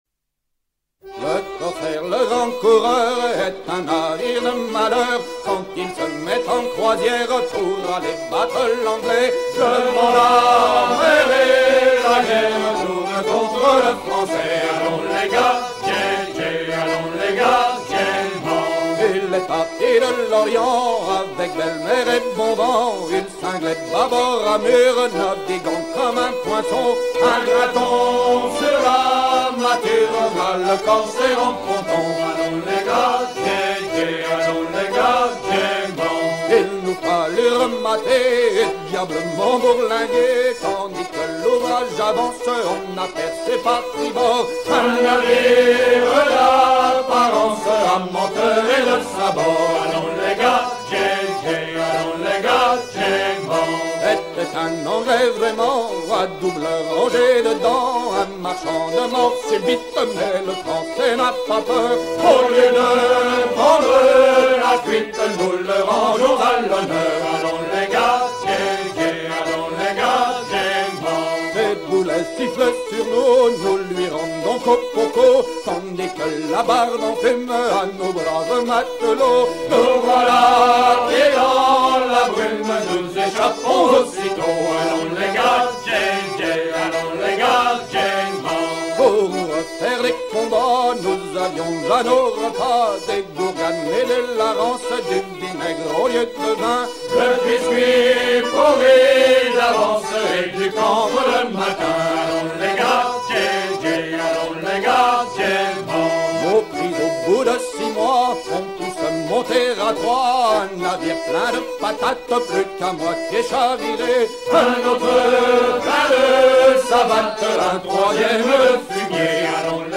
à virer au cabestan
Pièce musicale éditée